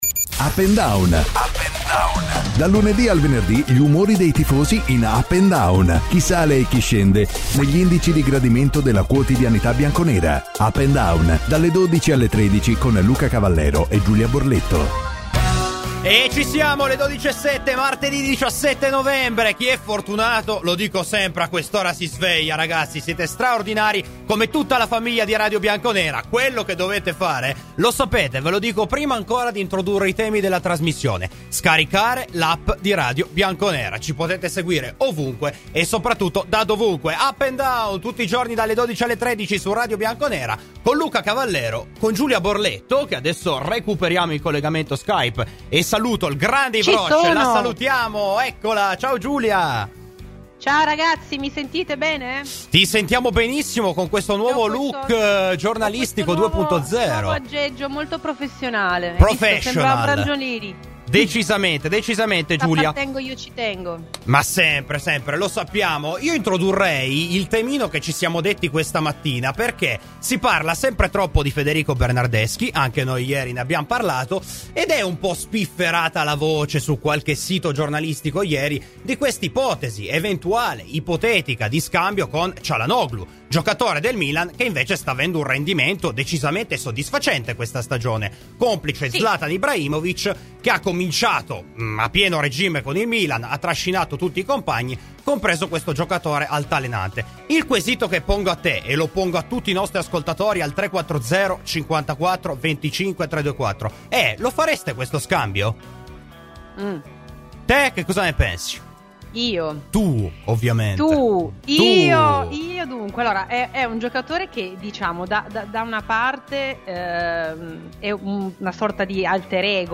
Ai microfoni di Radio Bianconera, nel corso di ‘Up&Down’, è intervenuto